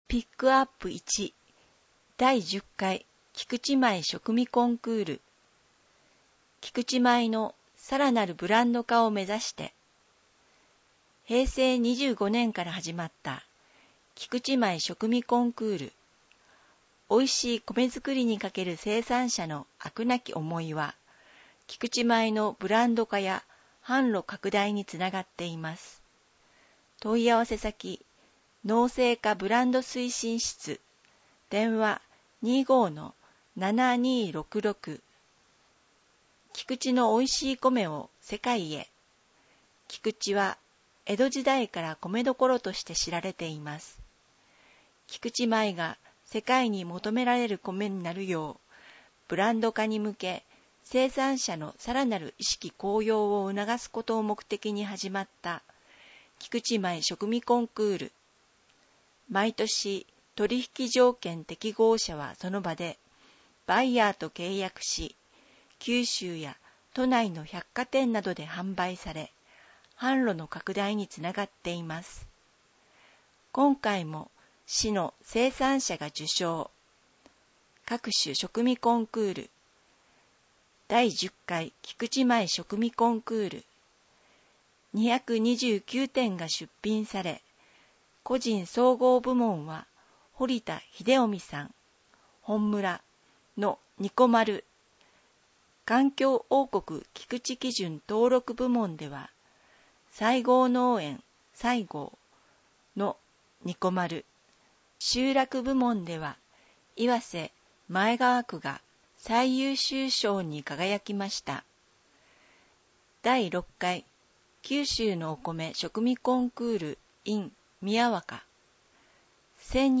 音訳